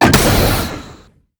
JackHammer_3p_01.wav